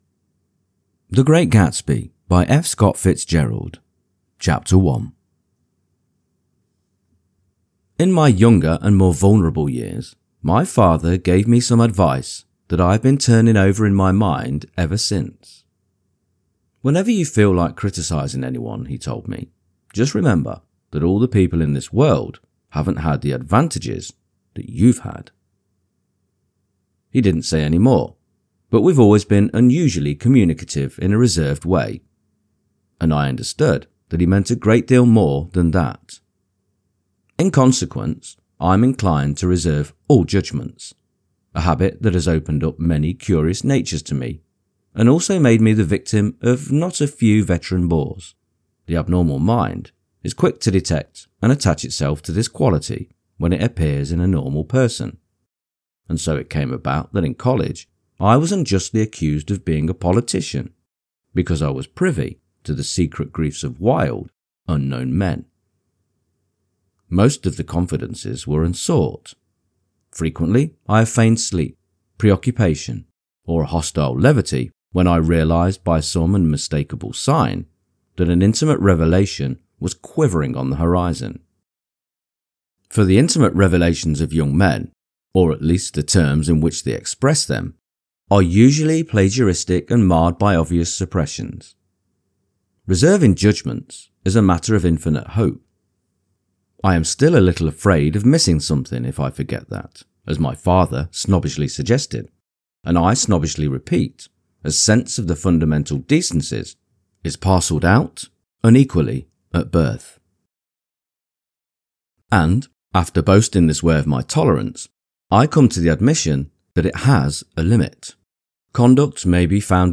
The Great Gatsby Audio-book – Chapter 1 | Soft Spoken English Male Full Reading (F.Scott Fitzgerald) - Dynamic Daydreaming